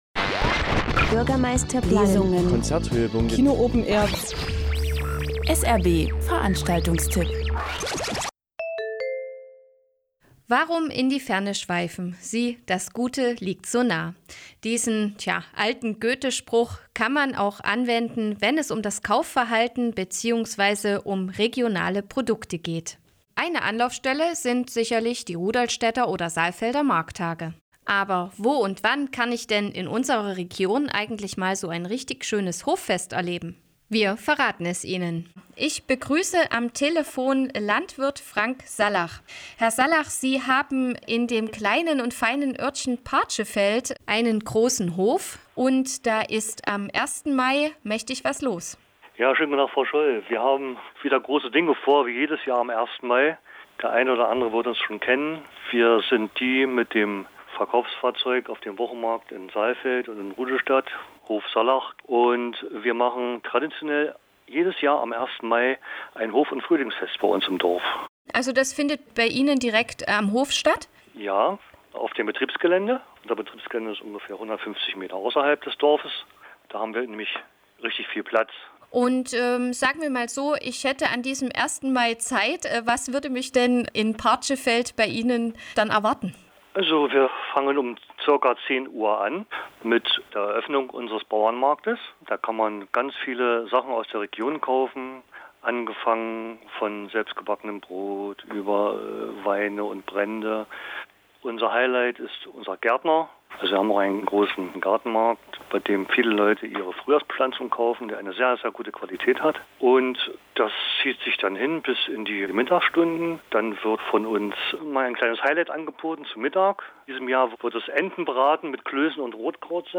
SRB-Interview zum Hof- und Frühlingsfest 2018